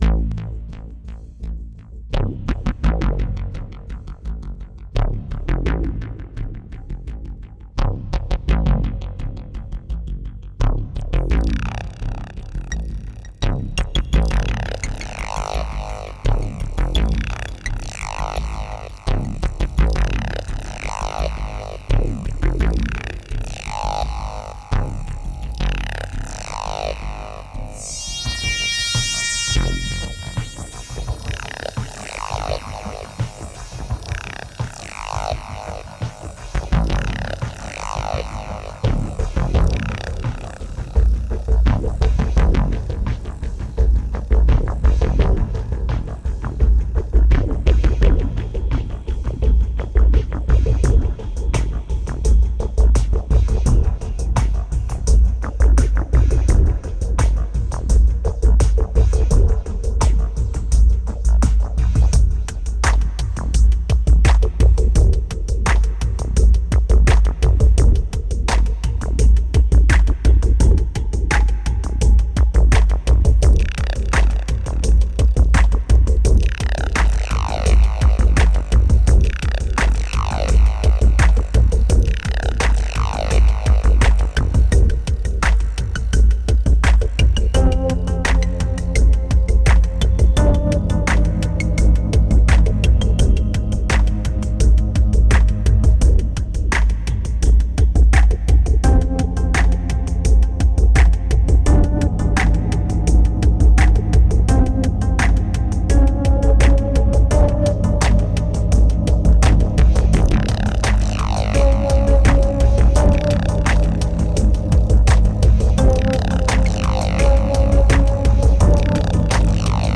2017📈 - 18%🤔 - 85BPM🔊 - 2012-10-07📅 - -46🌟